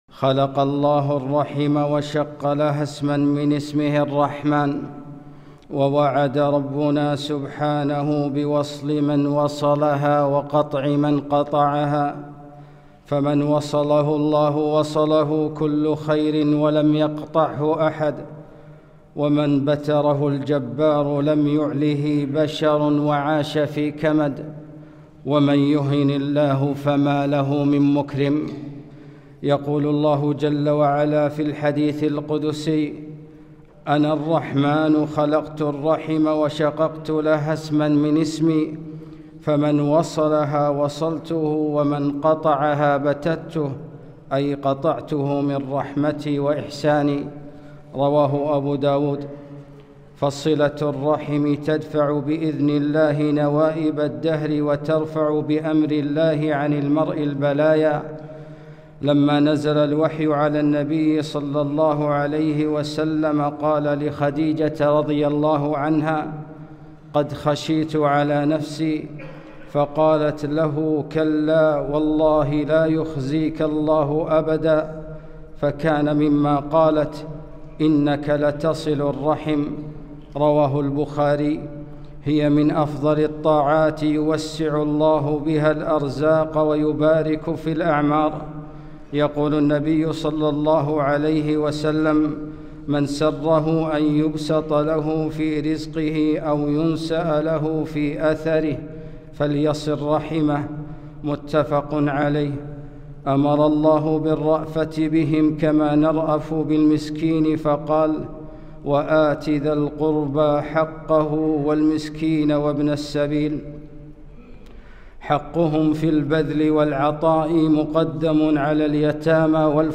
خطبة - السماء مغلقة دونه